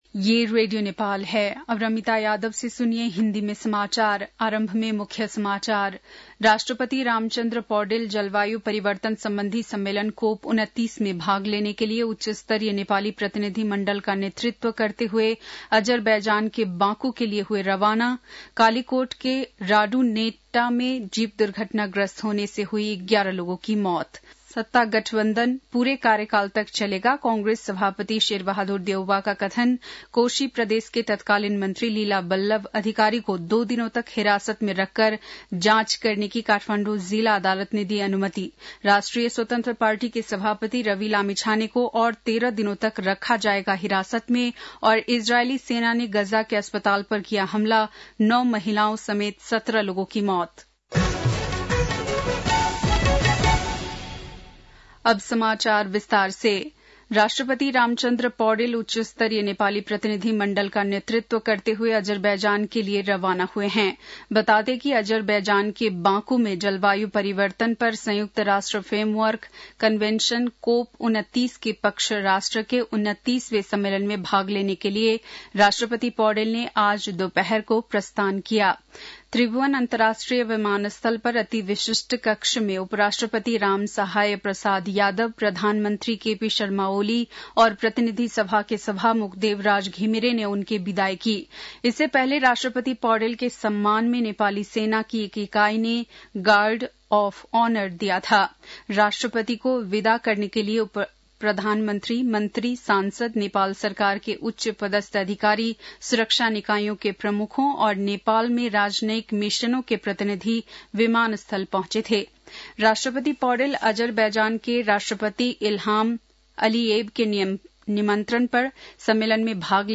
बेलुकी १० बजेको हिन्दी समाचार : २६ कार्तिक , २०८१
10-pm-hindi-news.mp3